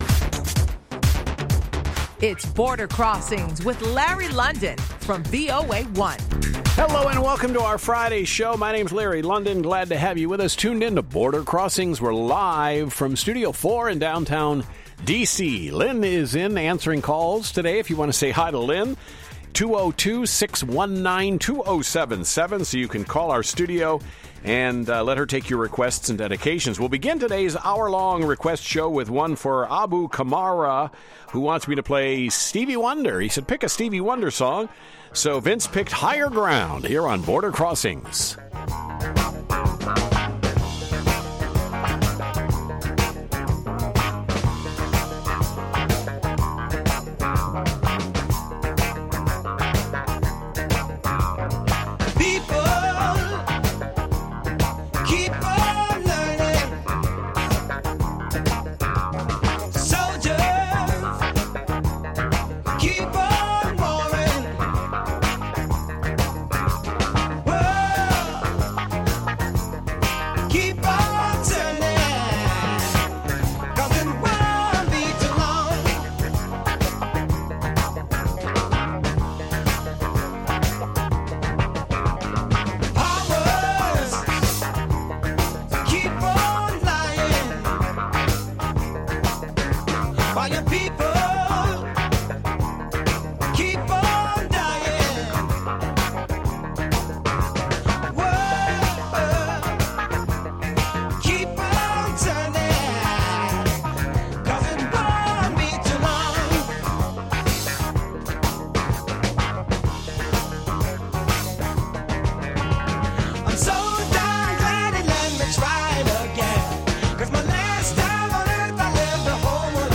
live worldwide international music request show